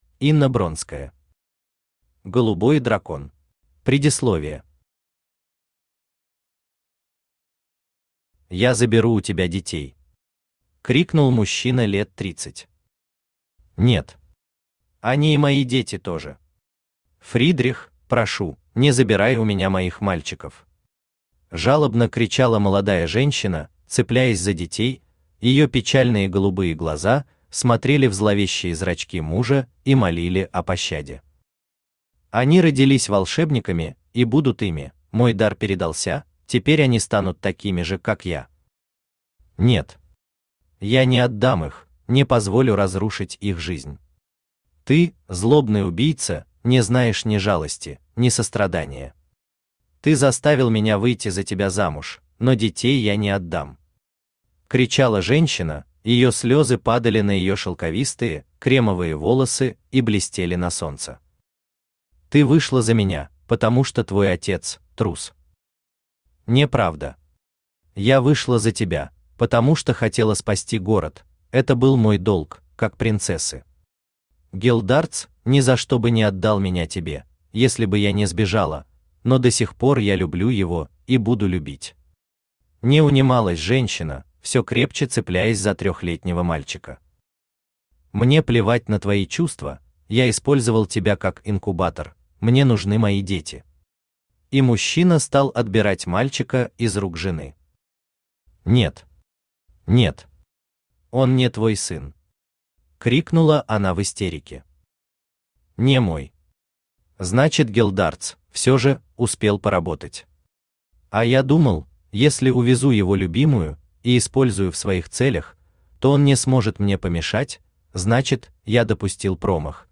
Aудиокнига Голубой дракон Автор Инна Дмитриевна Бронская Читает аудиокнигу Авточтец ЛитРес.